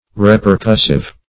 Repercussive \Re`per*cuss"ive\ (-k?s"?v), a. [Cf. F.